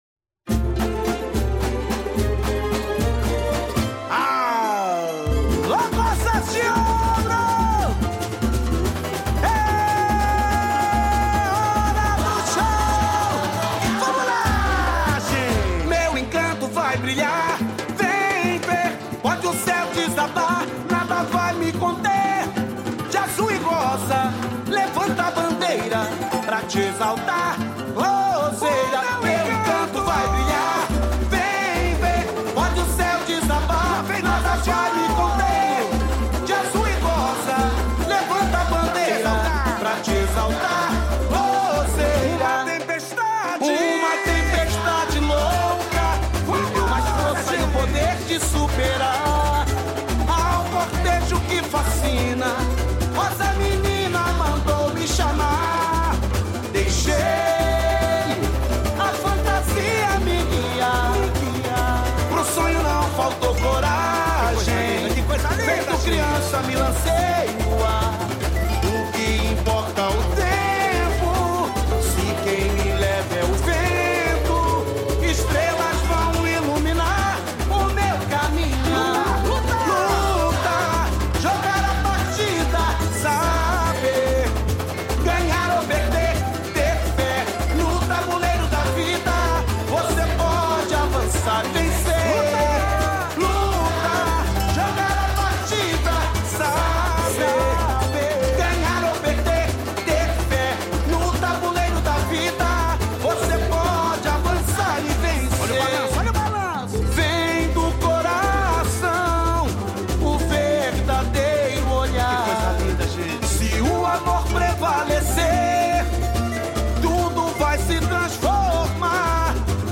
Interprete: